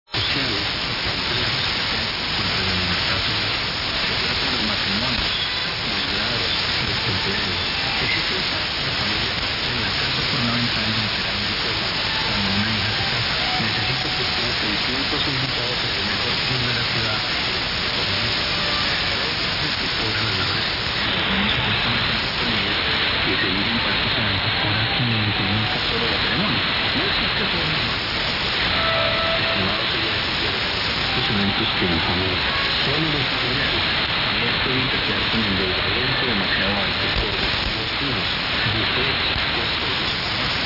Piccolo log di ascolti notturni, sono volontariamente state omesse tutte le stazioni spagnole in onde medie visto che si ricevono pure attaccando uno spezzone di filo agli orecchini 😉
Ricevitore SDR Play 1, antenna Maxiwhip, Grid: JN45rt